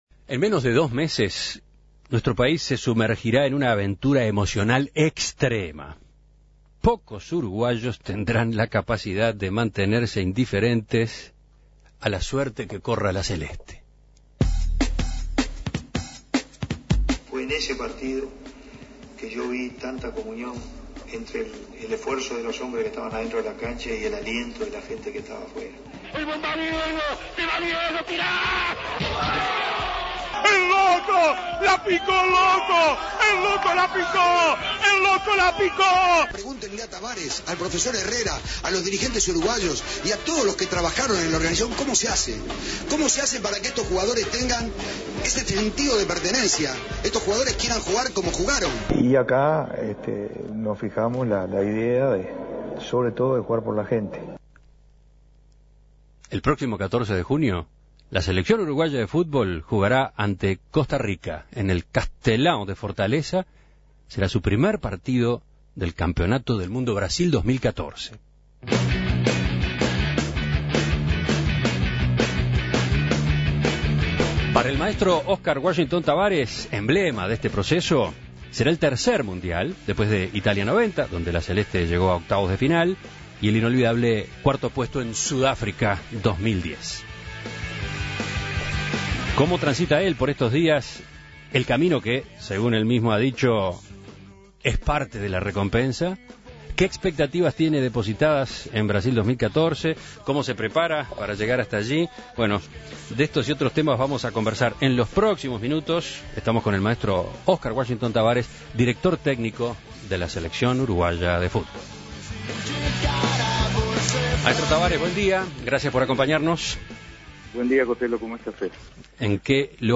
El director técnico de la selección, Ã"scar Tabárez, habló con En Perspectiva sobre estos meses que quedan de preparación, el cuerpo técnico, sus planes luego de la copa, el "Maracanazo" y la situación de la Asociación Uruguaya de Fútbol.